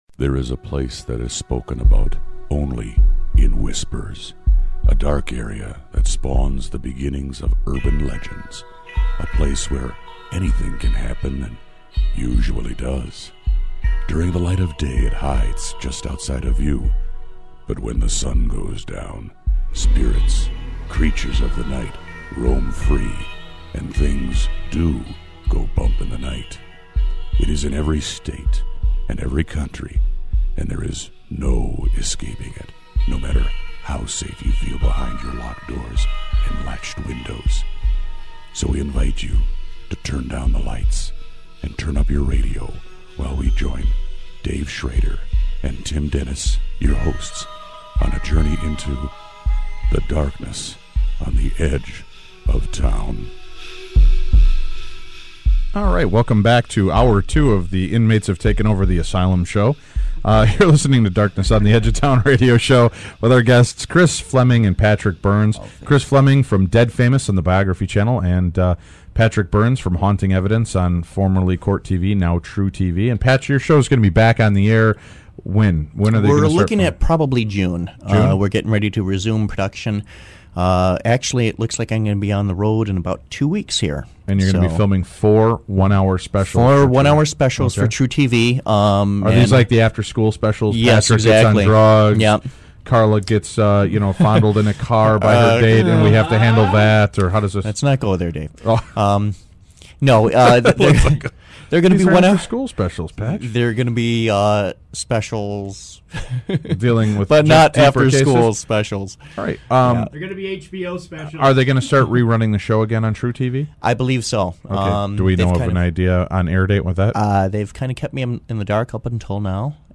are in studio with Darkness Radio after our event at the Palmer House Hotel! We dissect the weekend and lay out the evidence. and also compare it to other similar cases...